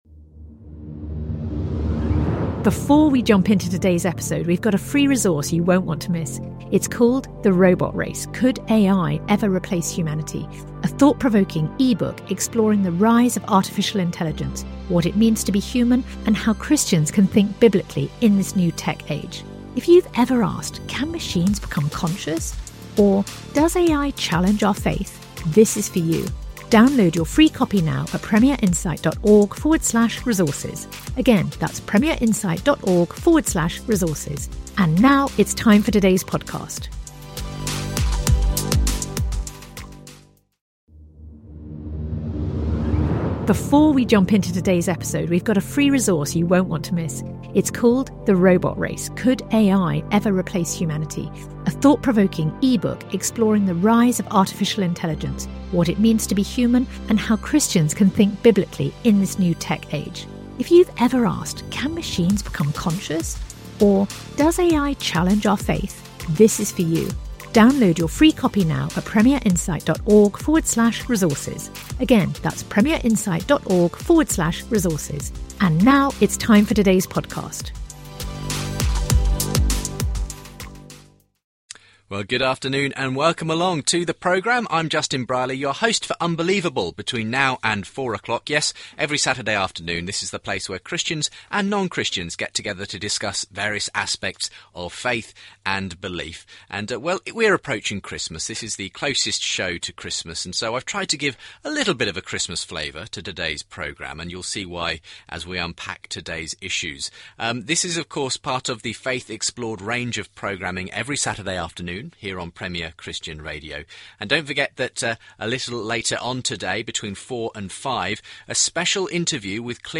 Rabbi Shmuley Boteach & Michael Brown debate